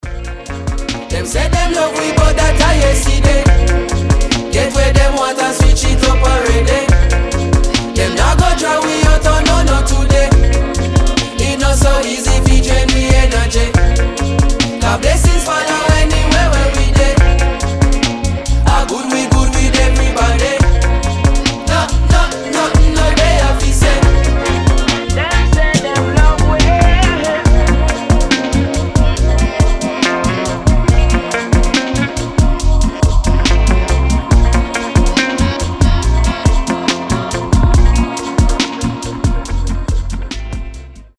• Качество: 320, Stereo
Хип-хоп